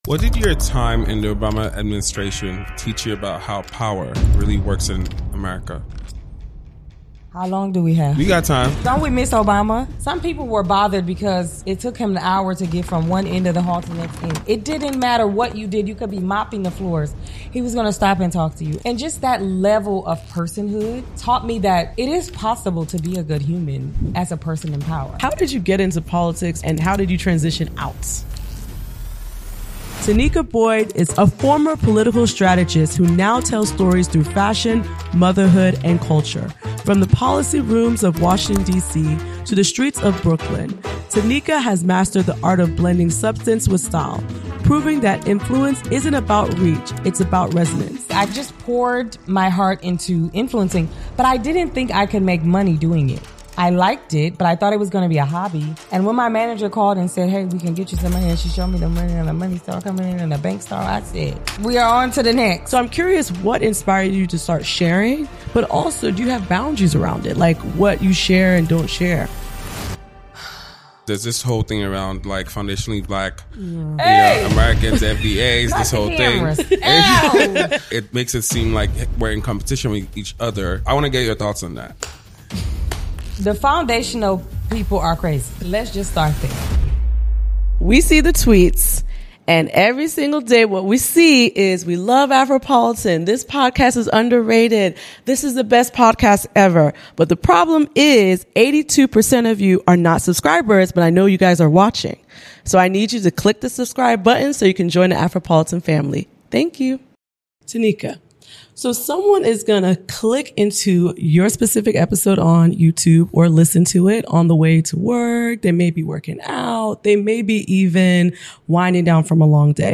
vulnerable conversation on the "speed of life."